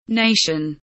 nation kelimesinin anlamı, resimli anlatımı ve sesli okunuşu